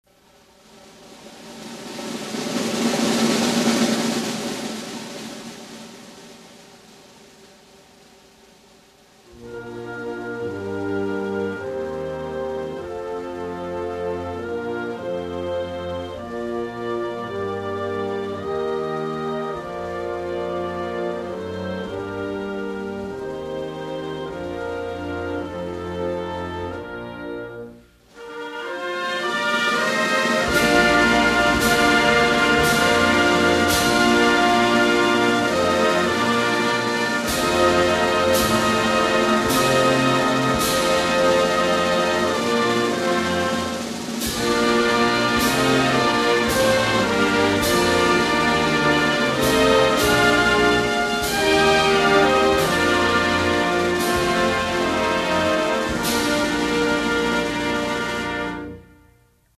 MP3 hudba + státní hymna ... + pochází ze stránek amerického vojenského hudebního tělesa United States Navy Band , kde má status – Public domain (jako dílo federální vlády USA je tento soubor volným dílem).